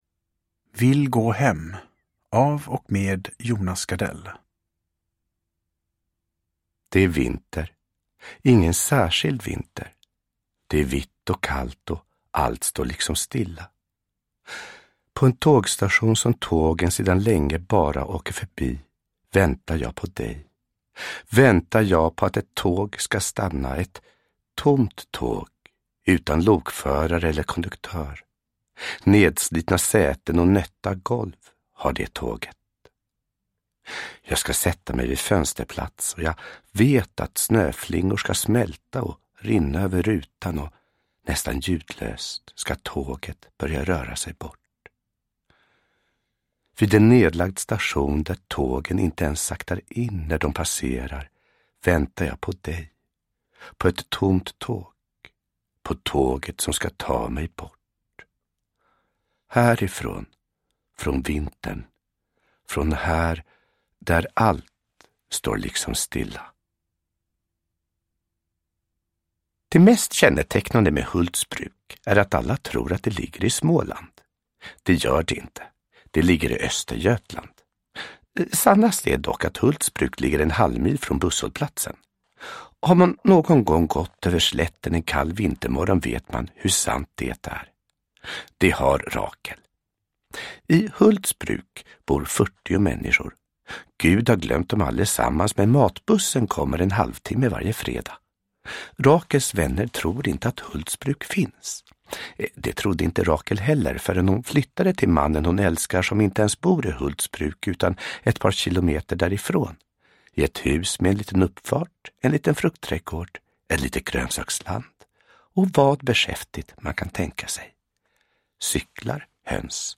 Vill gå hem – Ljudbok – Laddas ner
Uppläsare: Jonas Gardell